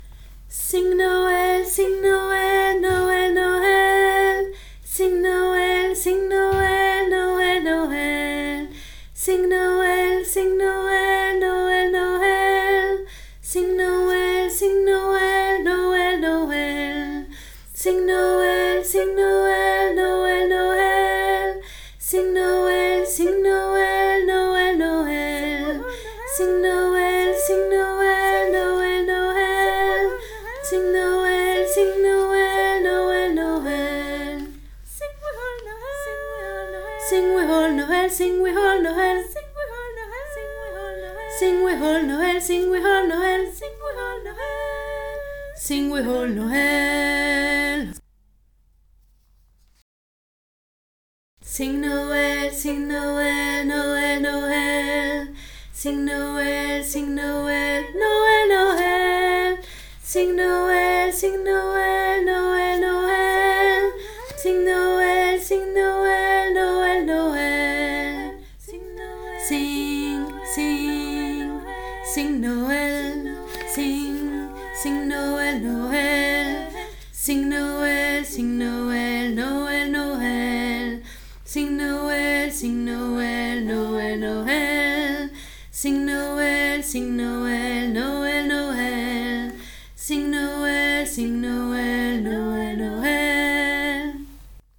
CHOEUR EPEHEMERE 2024
Sing Noel hommes et femmes graves
sing-noel-hommes-et-femmes-graves.mp3